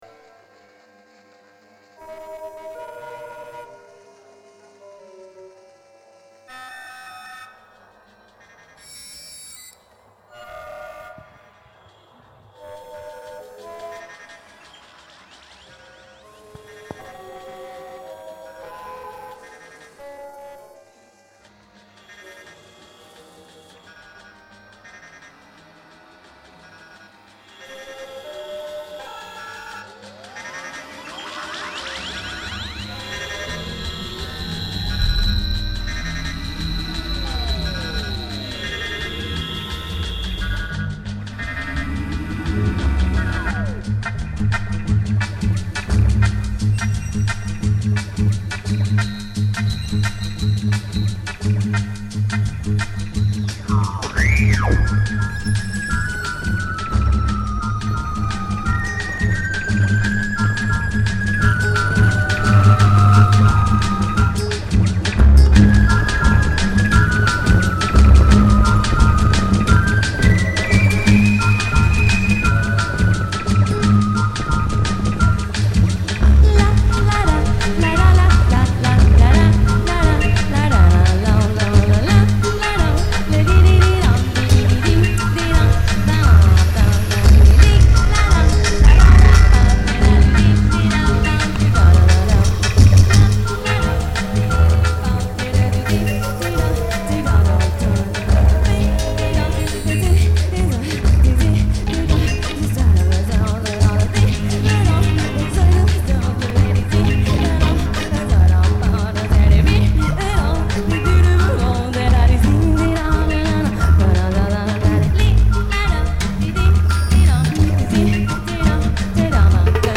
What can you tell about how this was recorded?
Recorded Live at EF Gallery Bar, Tokyo 2004